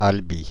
Albi (French pronunciation: [albi]
Fr-Albi.ogg.mp3